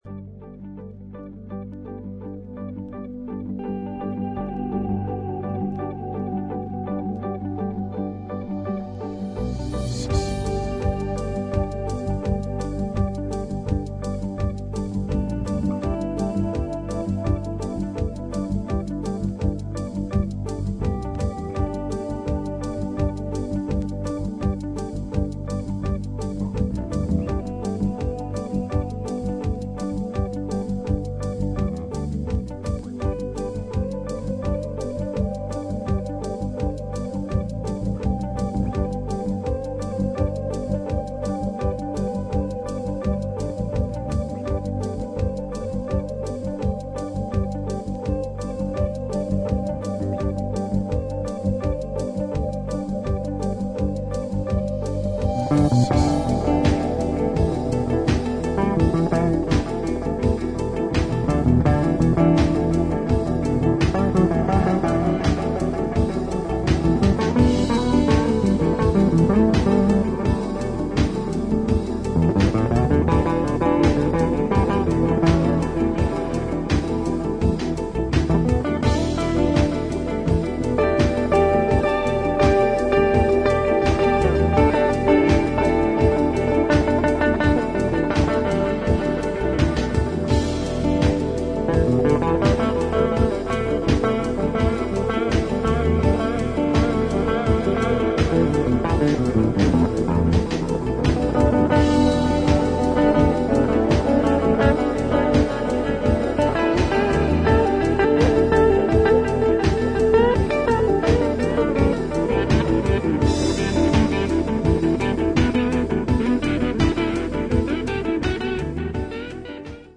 子気味良いギターのフレーズがレイヤーされ、浮遊感のある上音とブルージーなギターソロで展開していくスローなロックディスコ